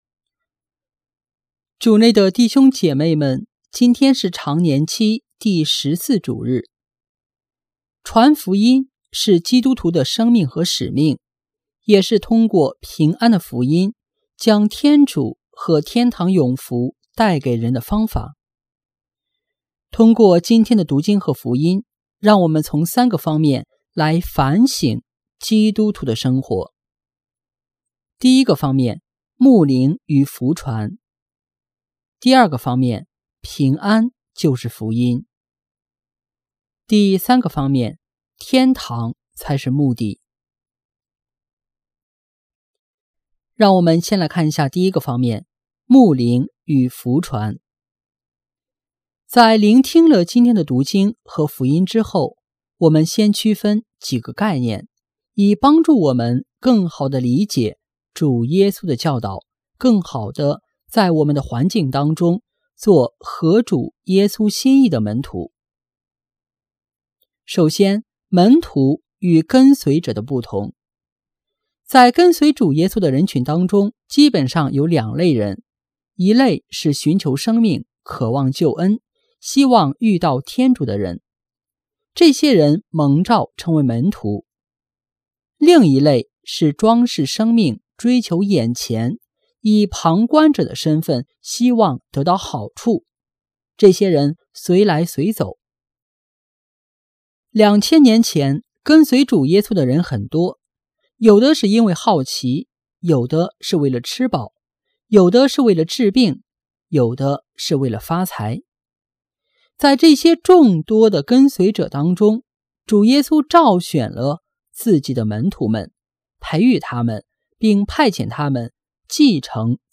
【主日证道】| 随主派遣传福音（丙-常年期第14主日）